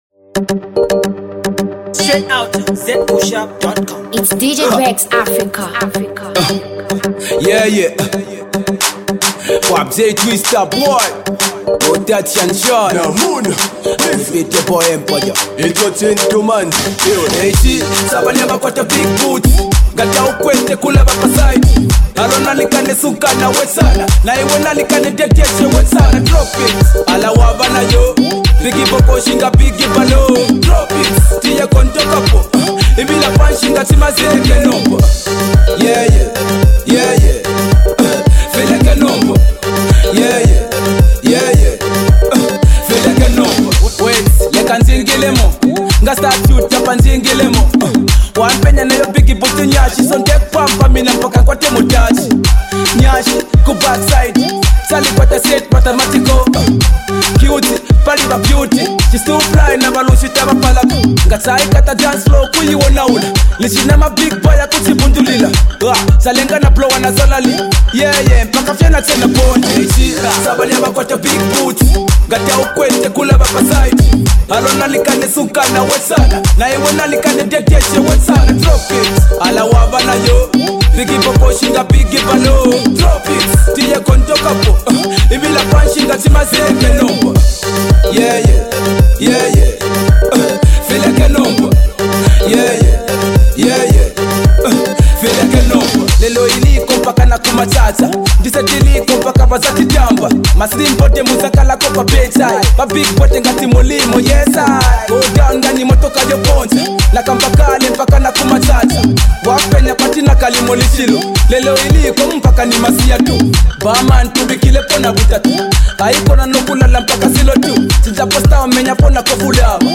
dance-hall banger